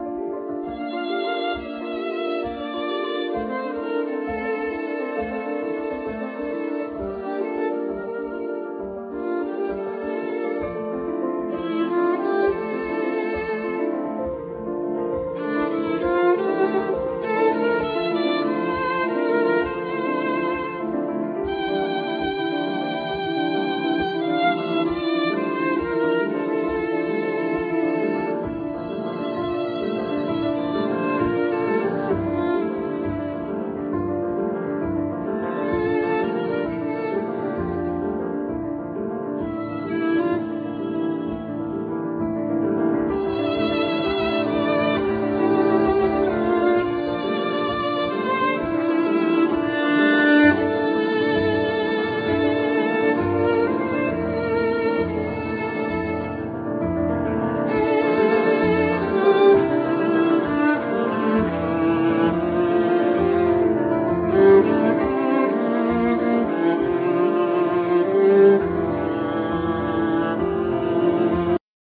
Piano
Viola
Cello